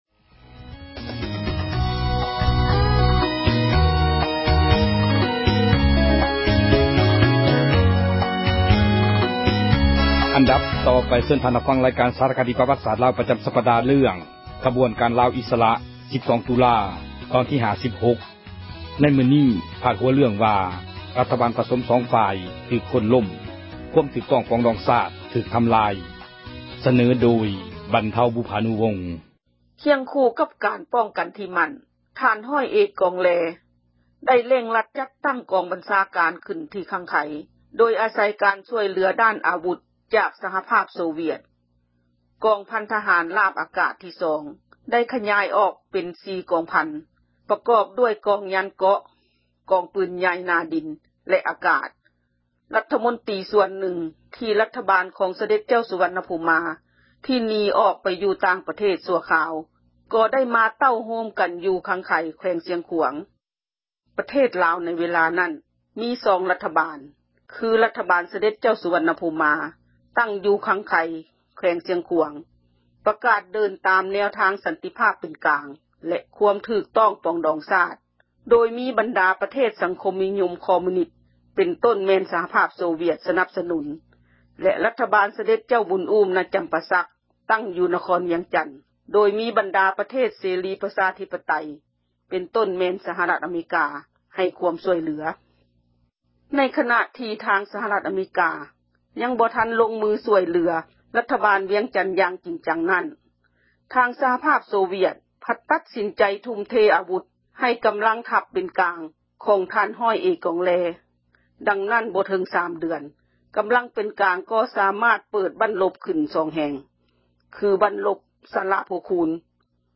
ເຊີນທ່ານຮັບຟັງ ຣາຍການ ສາຣະຄະດີ ປວັດສາດລາວ ປະຈໍາສັປດາ ເຣື້ອງ ”ຂະບວນການລາວອິສຣະ 12 ຕຸລາ” ຕອນທີ 56. ໃນມື້ນີ້ພາດ ຫົວເຣື້ອງວ່າ: ຣັຖບາລປະສົມ ສອງຝ່າຍຖືກ ໂຄ່ນລົ້ມ ຄວາມຖືກຕ້ອງ ປອງດອງຊາຕ ຖືກທໍາລາຍ.